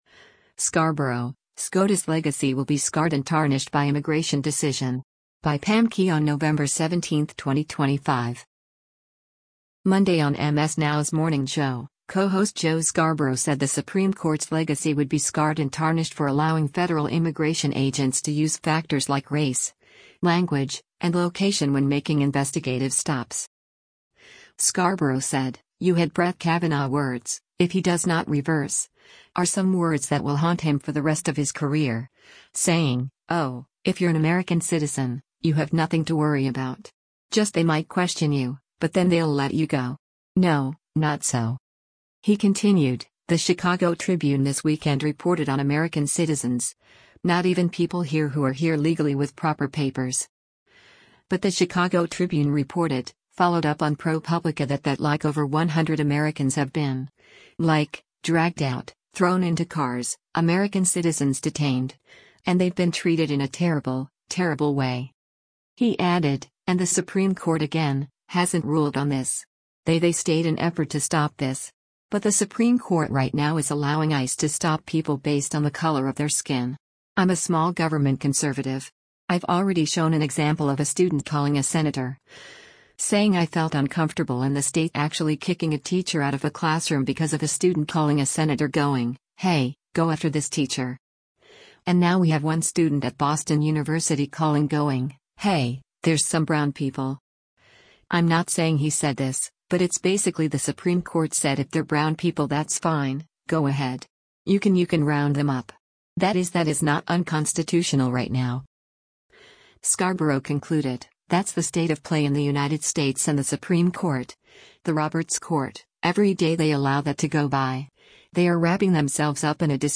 Monday on MS Now’s “Morning Joe,” co-host Joe Scarborough said the Supreme Court’s legacy would be “scarred and tarnished” for allowing federal immigration agents to use factors like race, language, and location when making investigative stops.